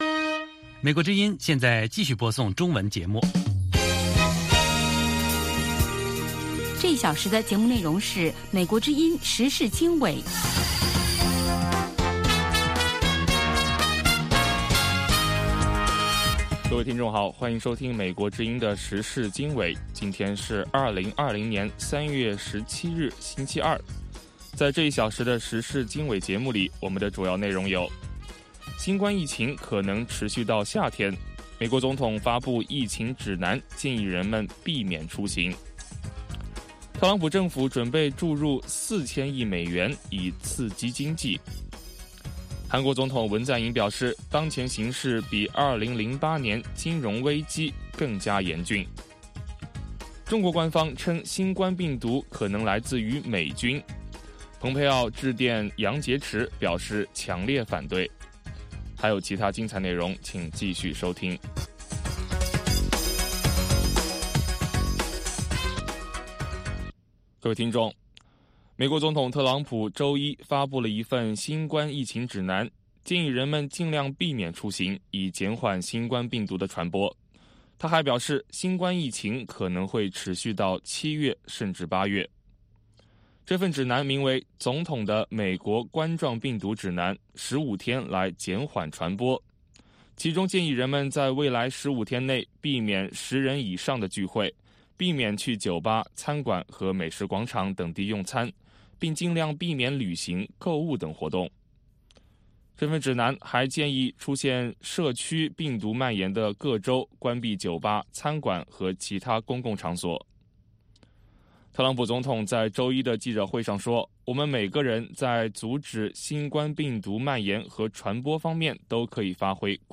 北京时间晚上6-7点广播节目